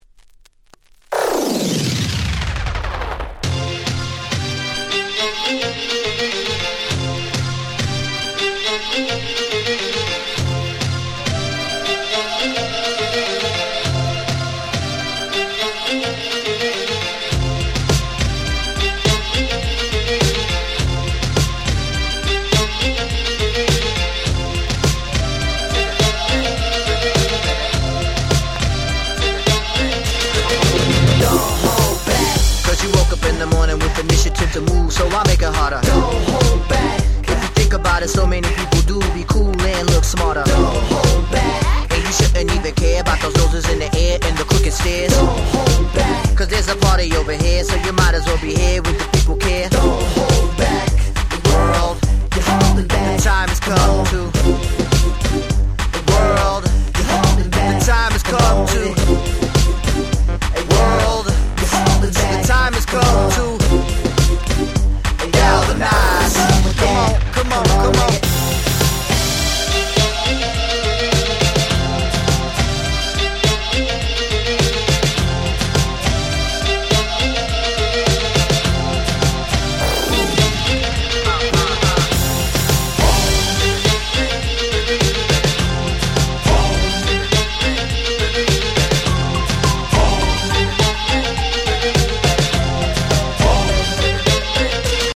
04' Super Hit Hip Hop / Break Beats !!
彼らの楽曲にしては非常にPopで分かり易い1曲！
Big Beat